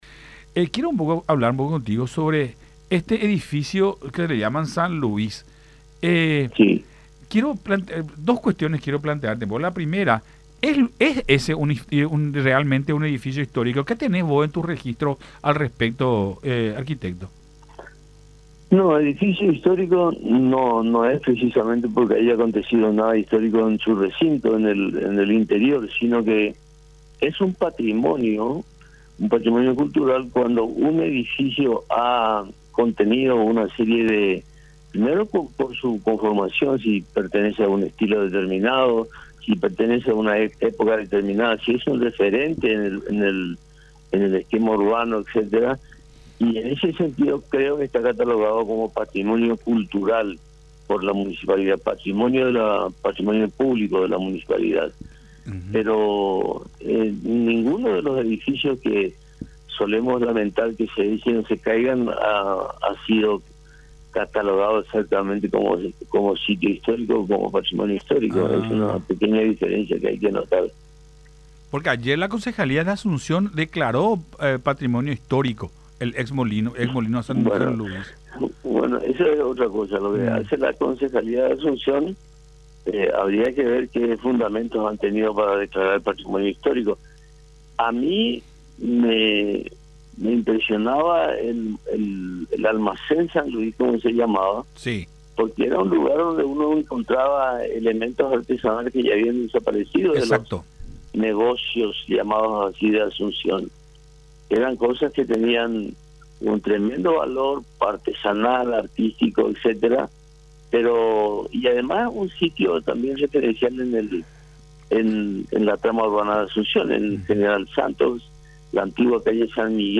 en diálogo con Nuestra Mañana por Unión TV